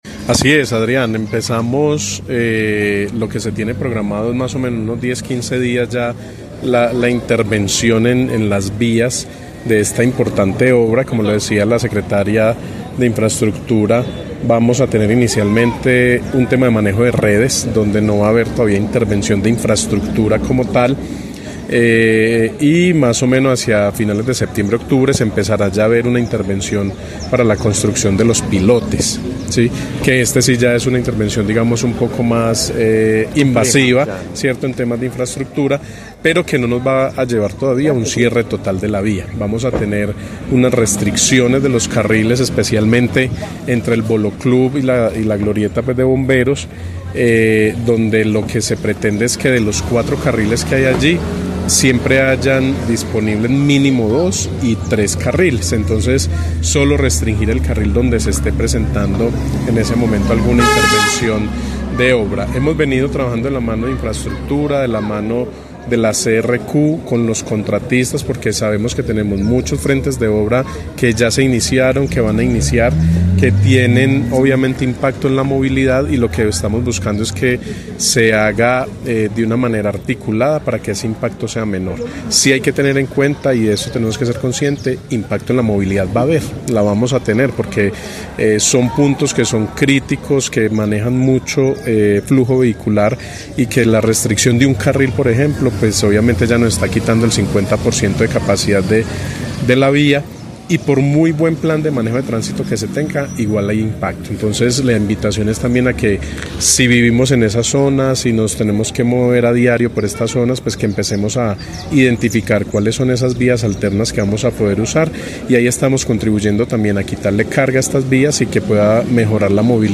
Daniel Jaime Castaño, secretario de tránsito de Armenia